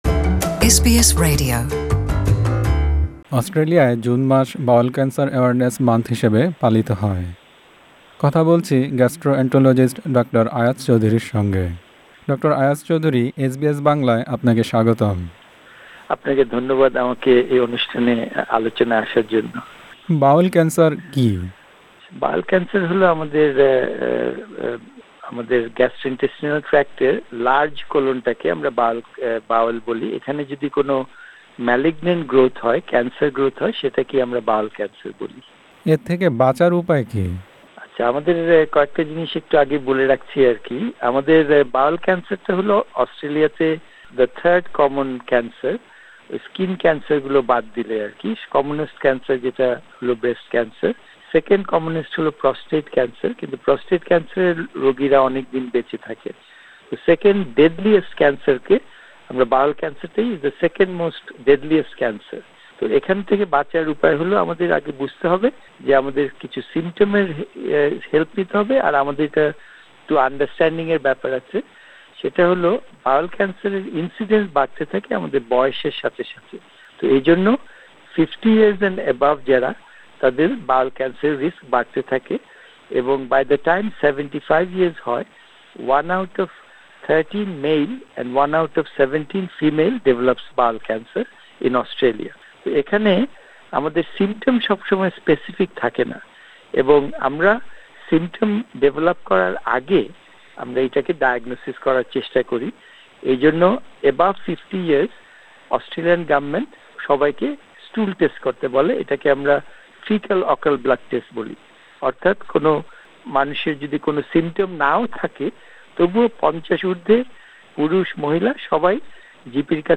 এসবিএস বাংলার সঙ্গে কথা বলেছেন গ্যাস্ট্রো-অ্যান্টোলজিস্ট